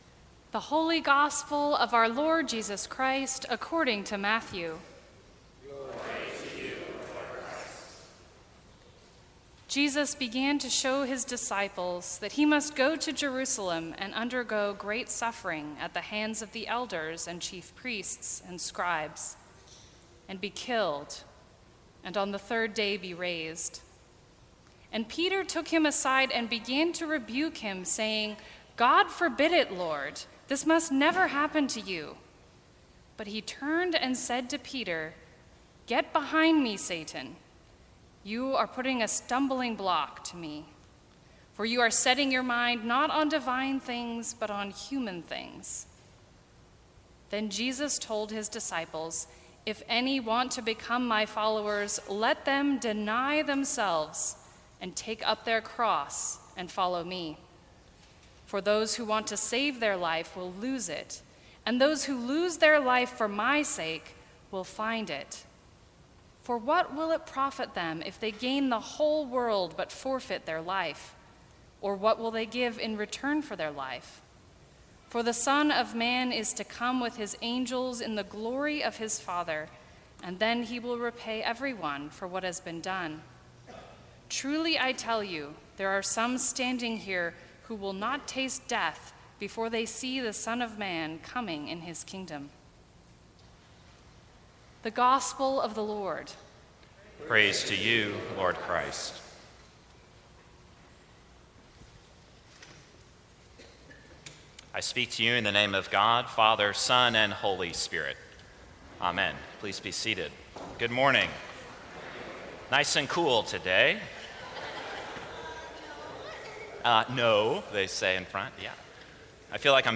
Thirteenth Sunday After Pentecost, 10 AM.
Sermons from St. Cross Episcopal Church The Path We Walk Sep 06 2017 | 00:12:31 1x 00:00 / 00:12:31 Subscribe Share Apple Podcasts Spotify Overcast RSS Feed Share Link Embed